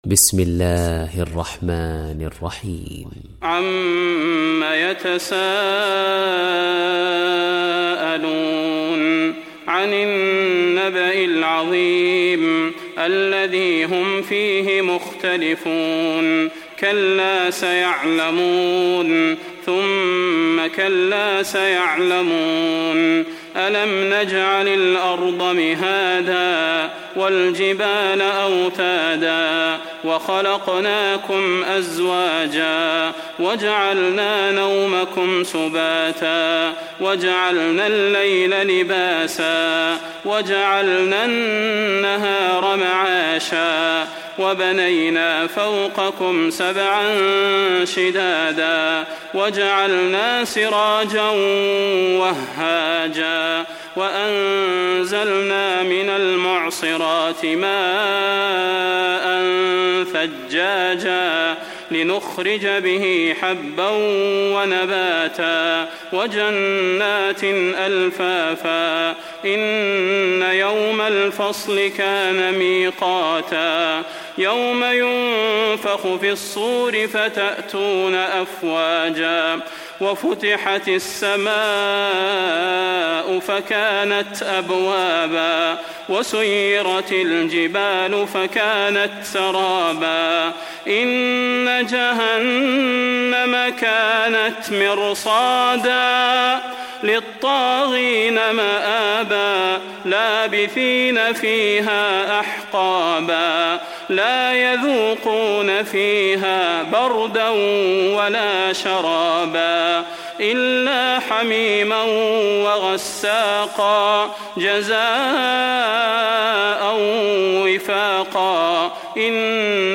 Surat An Naba Download mp3 Salah Al Budair Riwayat Hafs dari Asim, Download Quran dan mendengarkan mp3 tautan langsung penuh